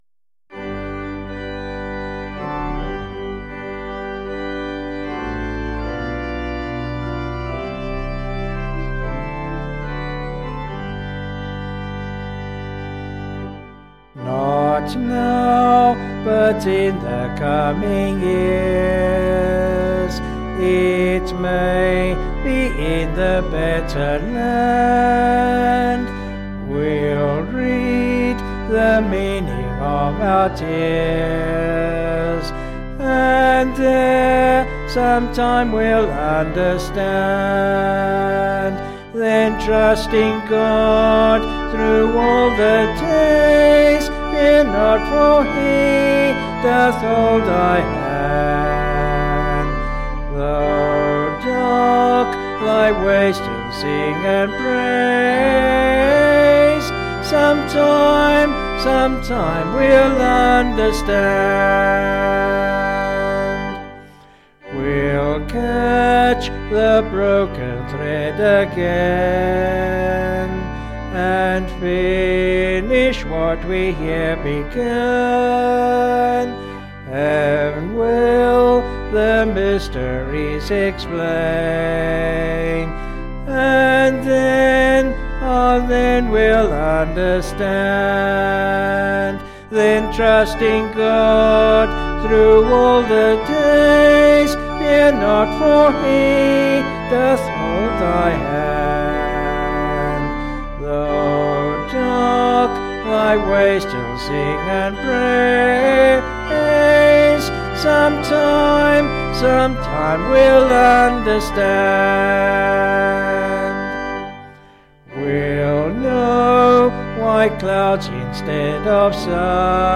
Vocals and Organ   264.9kb Sung Lyrics